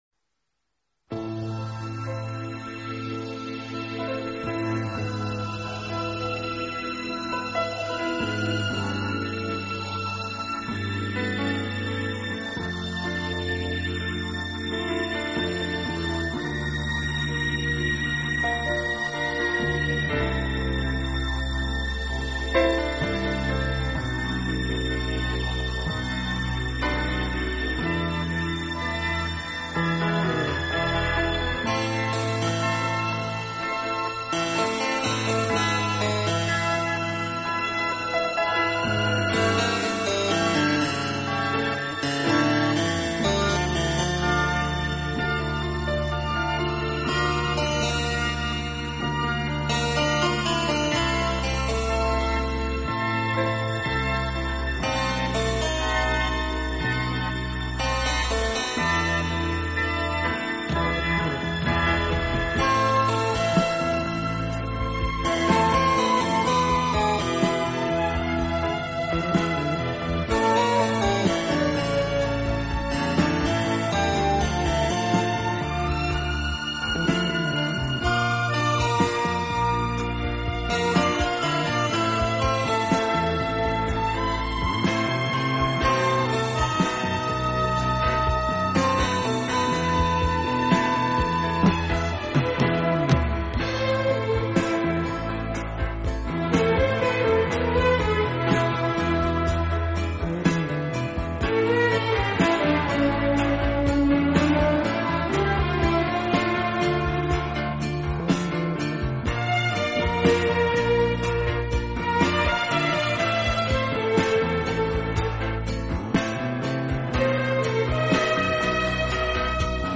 【顶级轻音乐】
好处的管乐组合，给人以美不胜收之感。